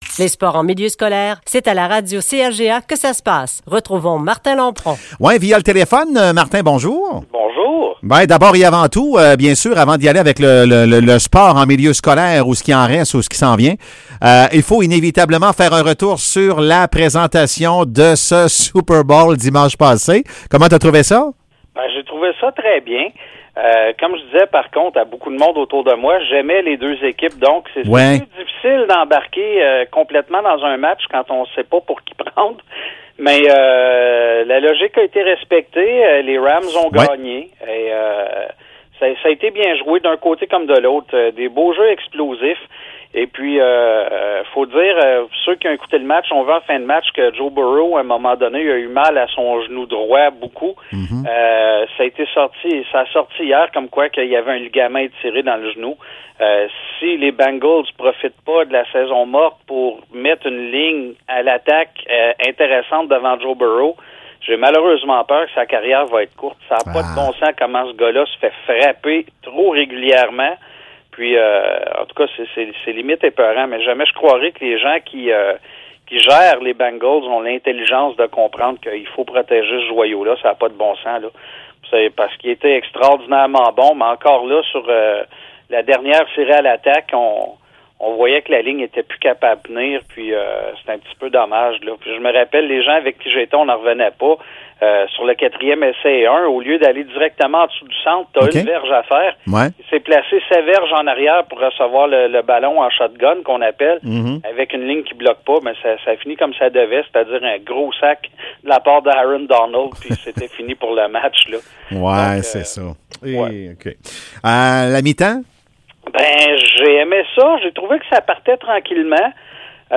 Chronique sports